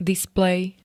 displej [d-] -ja pl. N -je m.
Zvukové nahrávky niektorých slov